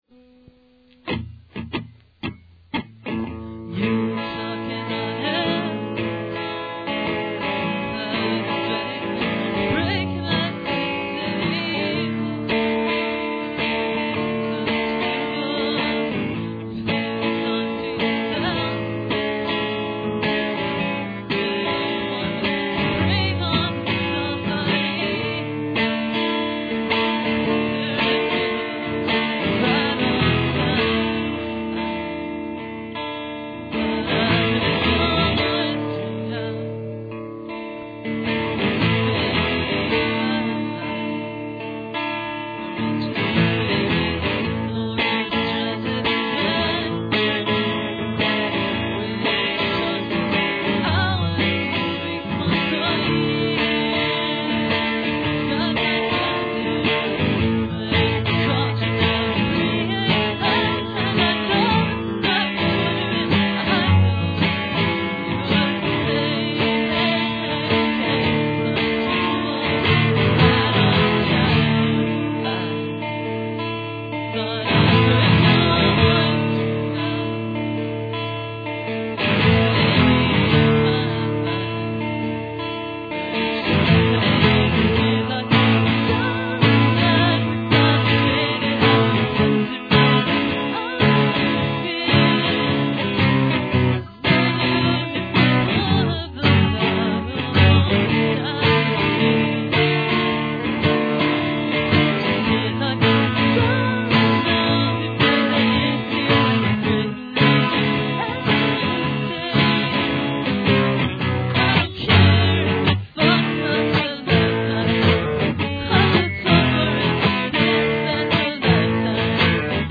Painstakingly recorded to normal bias generic brand audiotape